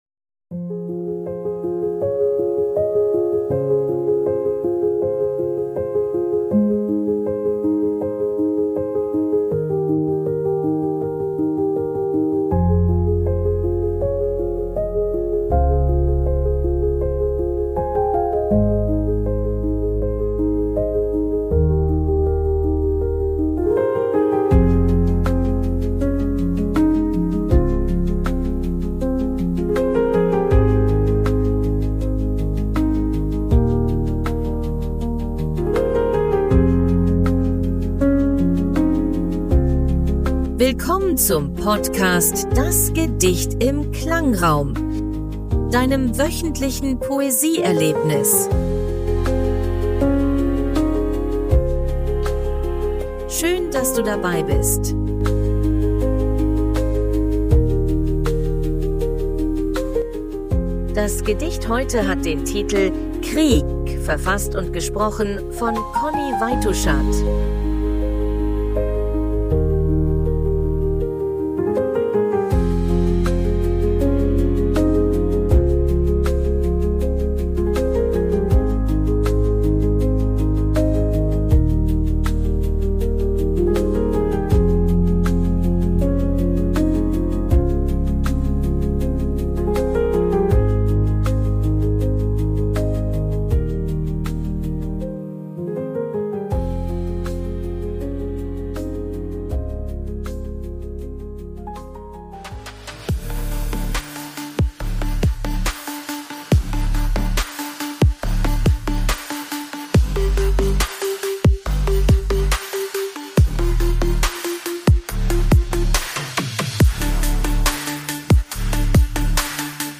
eines sorgfältig ausgewählten Gedichts, umrahmt von einer
stimmungsvollen Klangkulisse.
KI-generierter Musik.